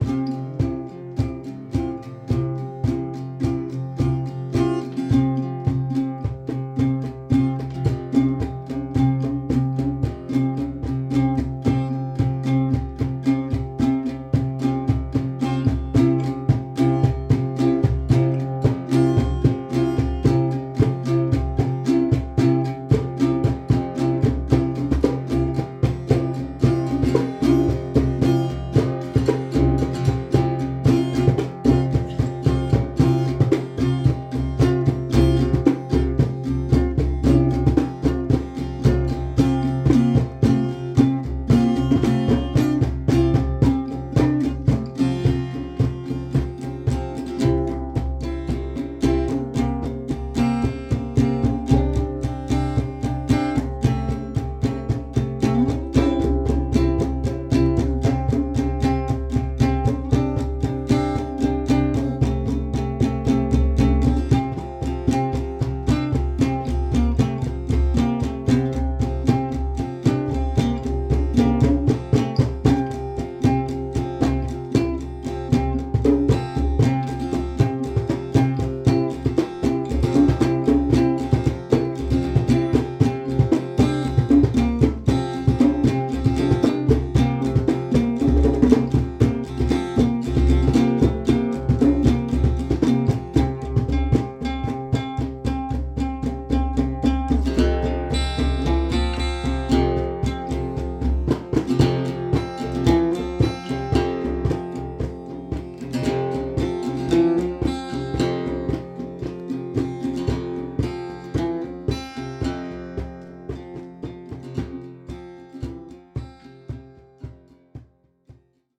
Day 46 Jam
07-5-Instrumental-again_mixed.mp3